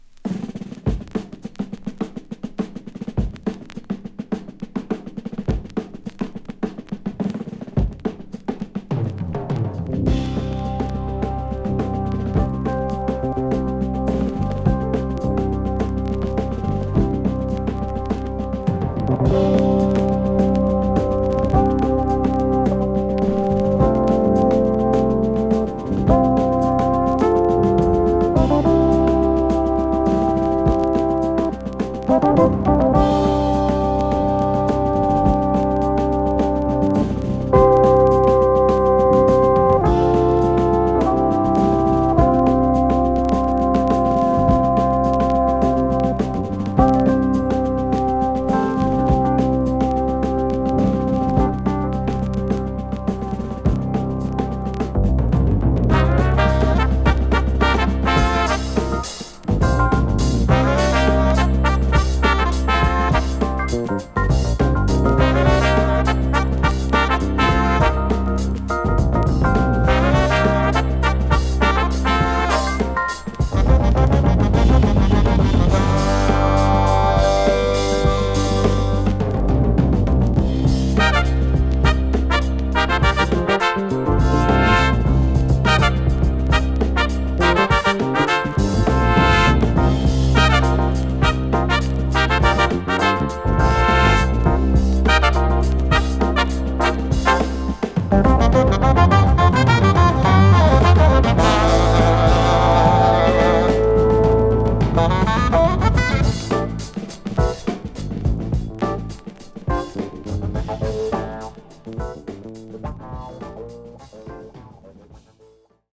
重厚なジャズファンク・フュージョン、トライバルなパーカッショングルーヴなど全曲必聴！